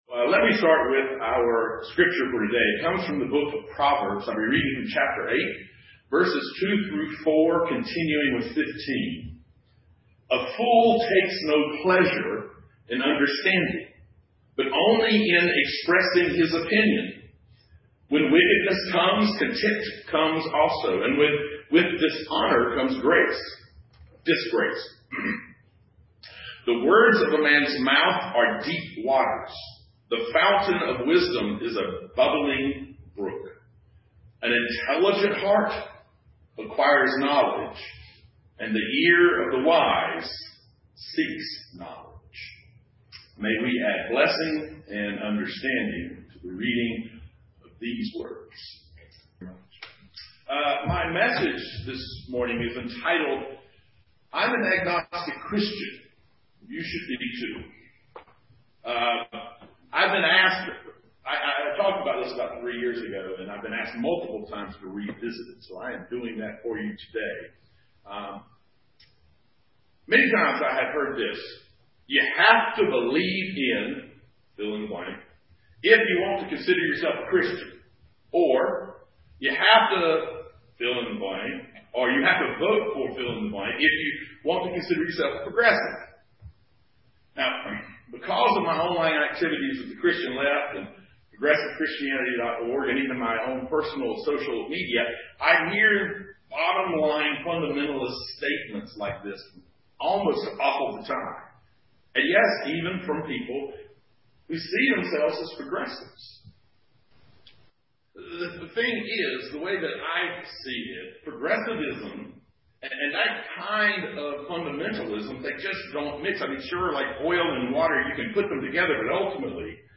Proverbs 8:2-15 (streamed on Facebook and Zoom)